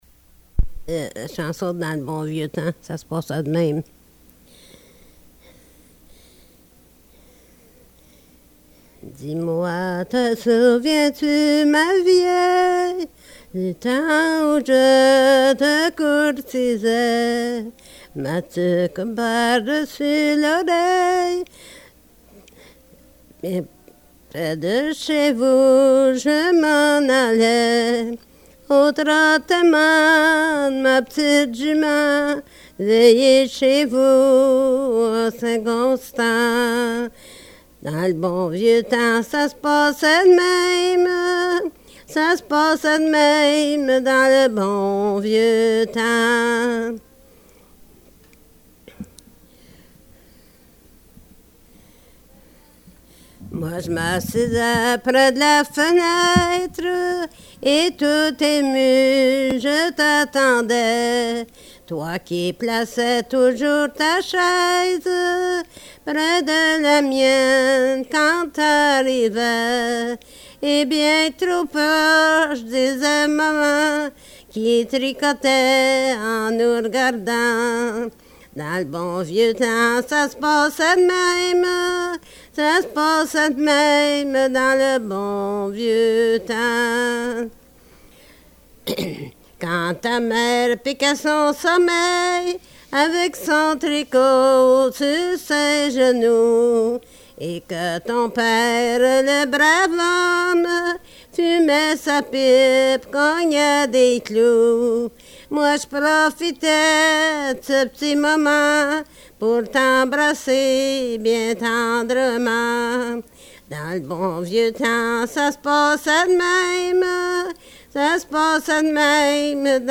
Folk Songs, French--New England
Excerpt from interview